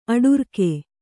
♪ aḍurke